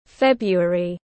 Tháng 2 tiếng anh gọi là february, phiên âm tiếng anh đọc là /ˈfeb.ru.ər.i/
February /ˈfeb.ru.ər.i/